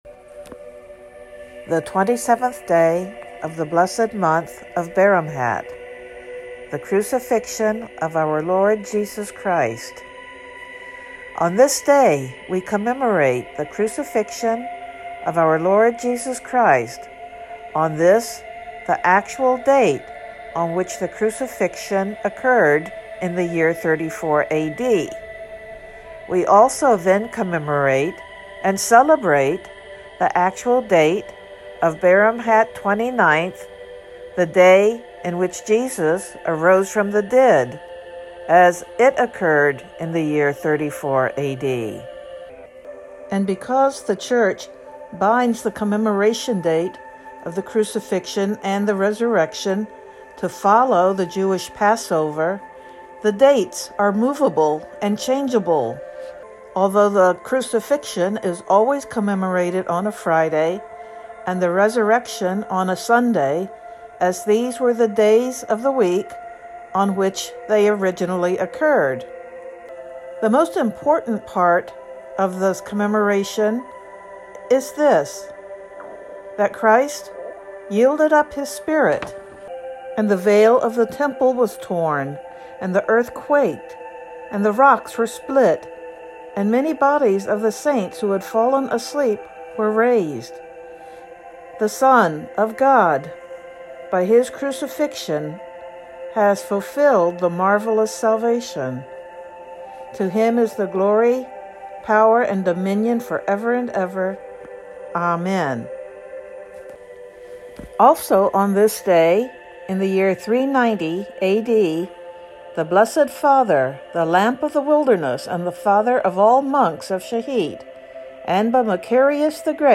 Synaxarium reading for 27th of Baramhat